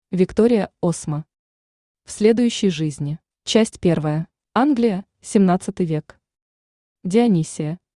Аудиокнига В следующей жизни | Библиотека аудиокниг
Aудиокнига В следующей жизни Автор Виктория Осма Читает аудиокнигу Авточтец ЛитРес.